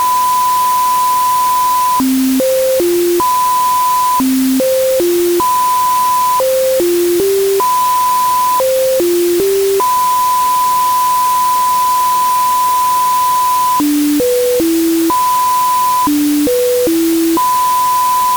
Audio: Mit dem gleichen Gerät: Autoruf im 4 m-Band vom Sender Säntis (mp3)
autorufR.mp3